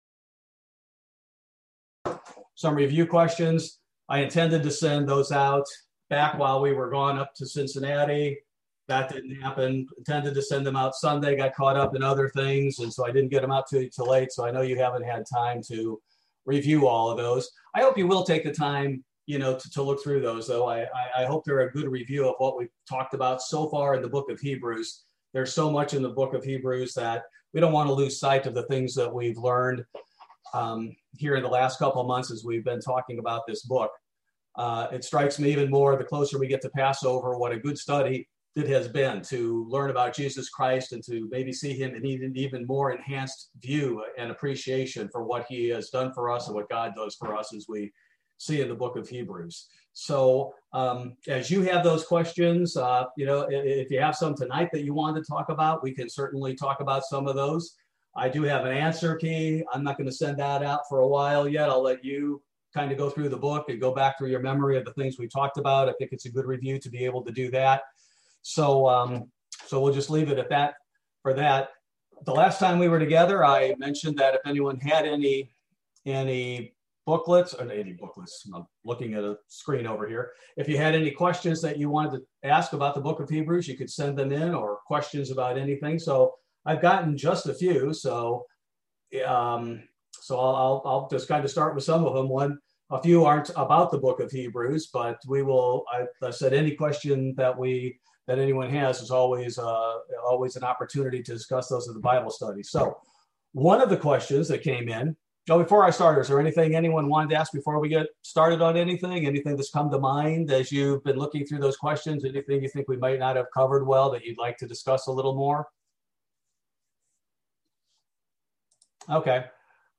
Bible Study: March 3, 2021